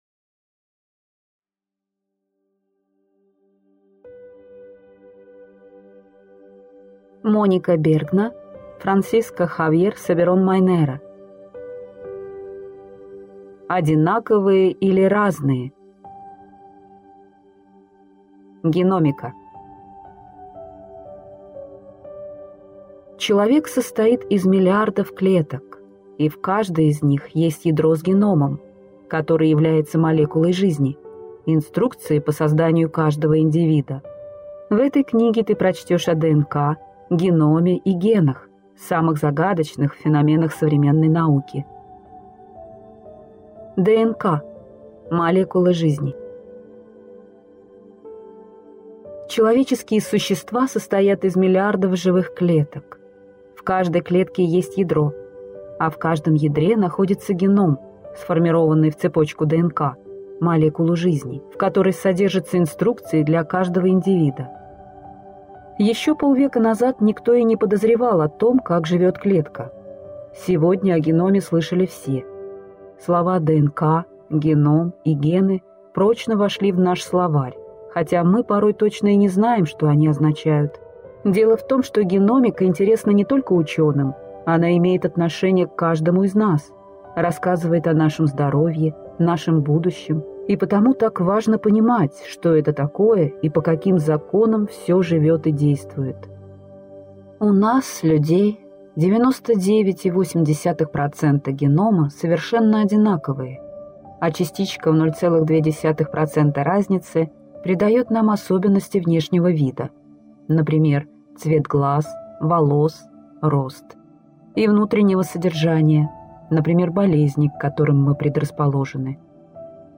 Аудиокнига Одинаковые или разные? Геномика | Библиотека аудиокниг